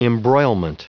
Prononciation du mot embroilment en anglais (fichier audio)
embroilment.wav